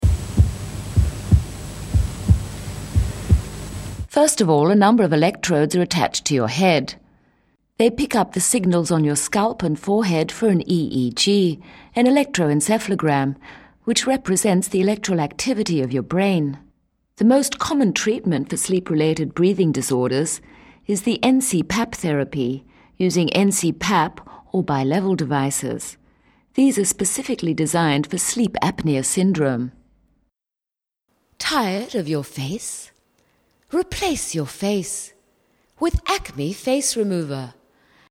englische (uk) Sprecherin, Muttersprache.
Sprechprobe: eLearning (Muttersprache):